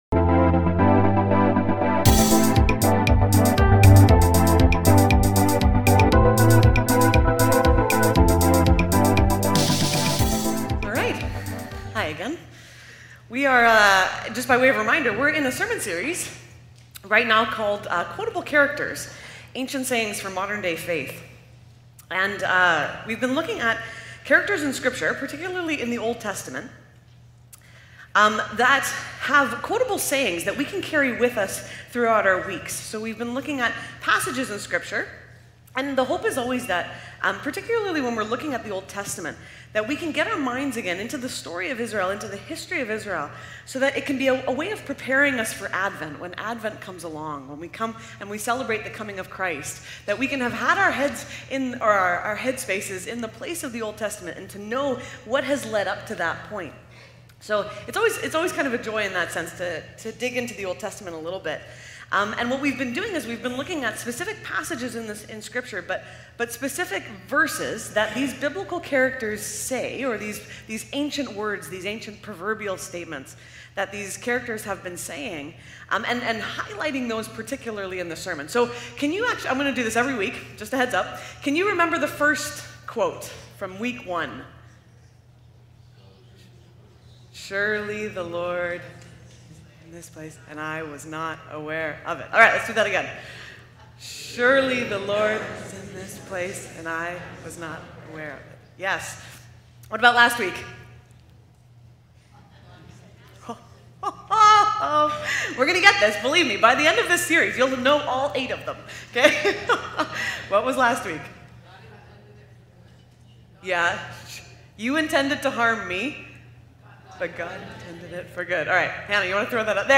Willoughby Church Sermons | Willoughby Christian Reformed Church